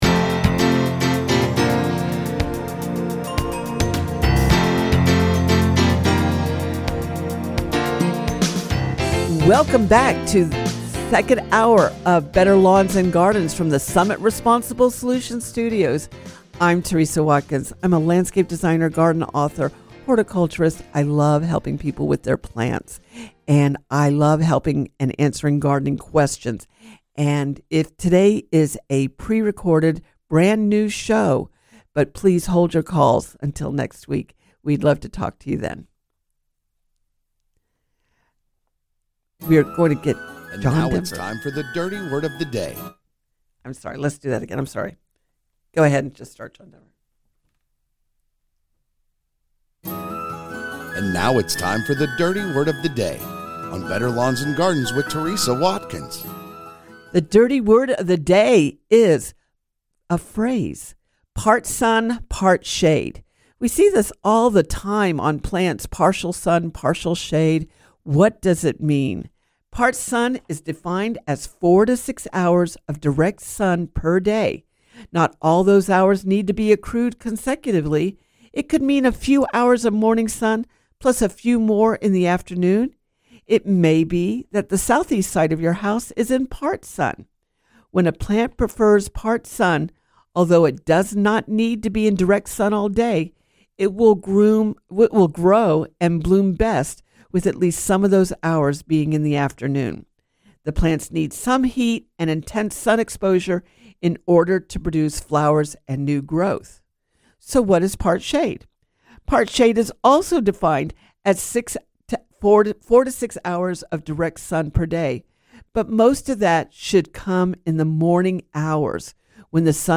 Better Lawns and Gardens Hour 1 – Coming to you from the Summit Responsible Solutions Studios.